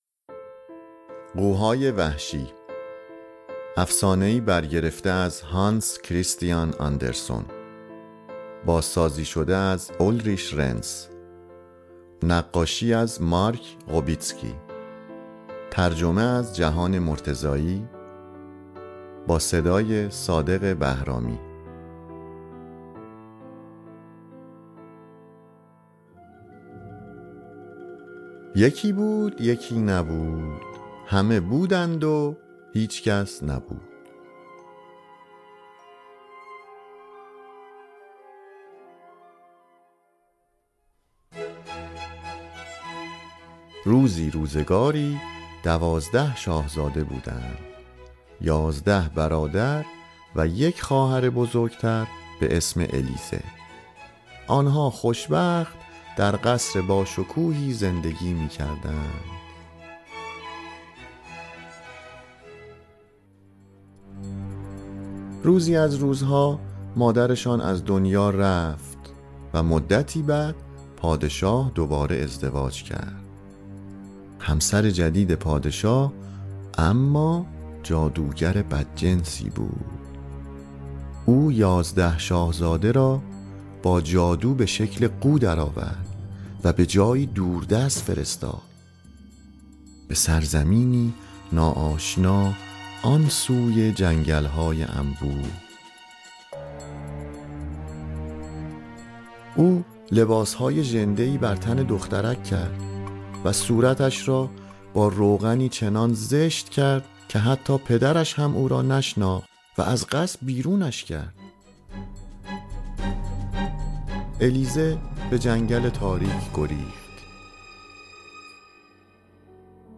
NEW! Listen to the story read by native speakers!